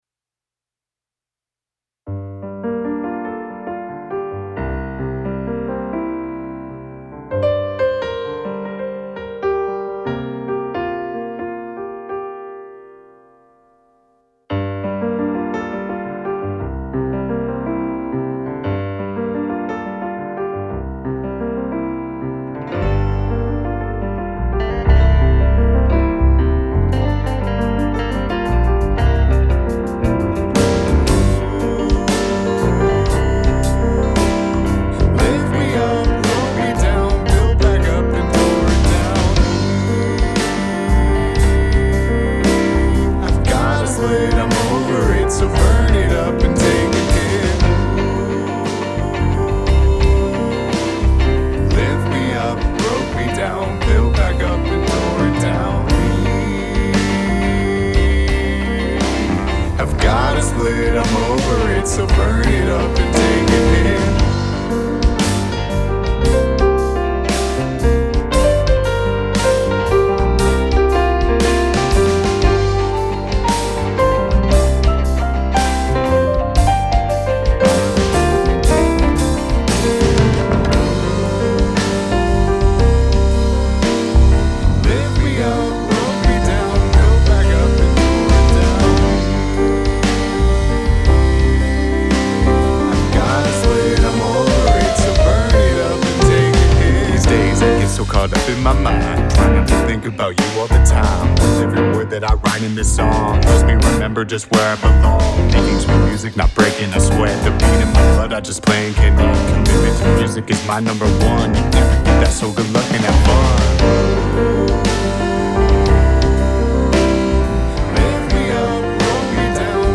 creates original loops and beats